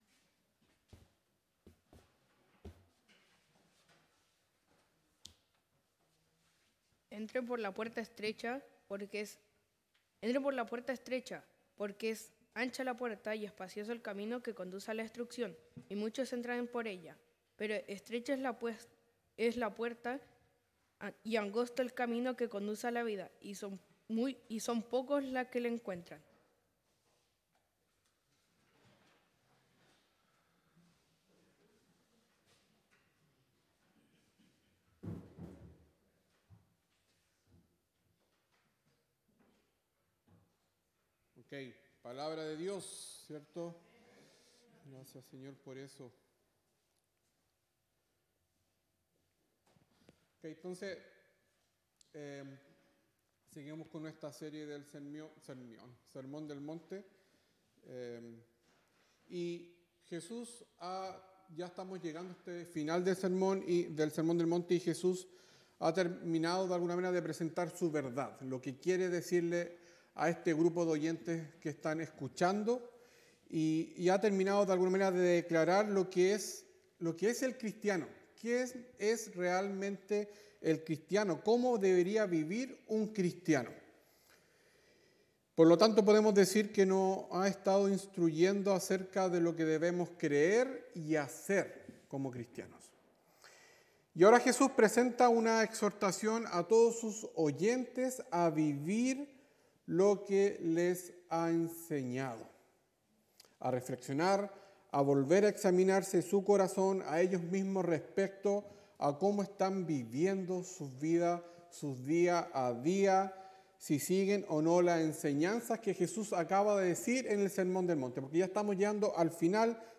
Sermones
Categoría: Sermones